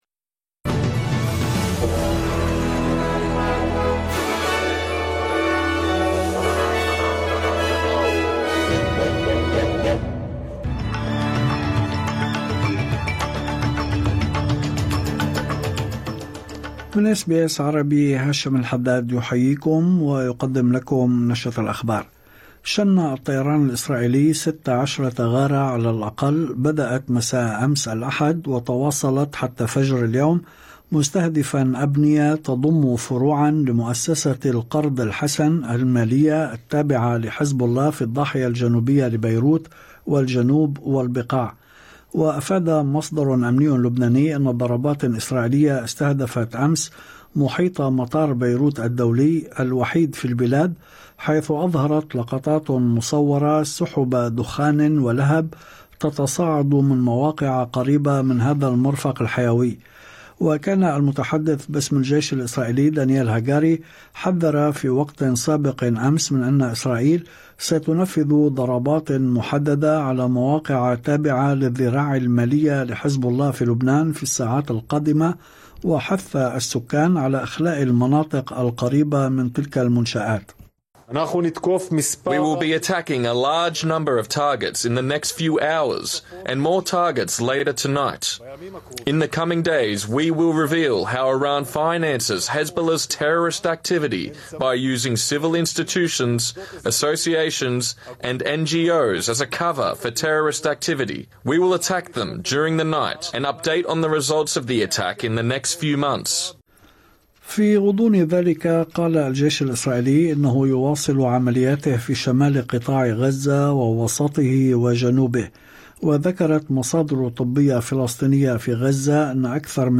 نشرة أخبار الظهيرة 21/10/2024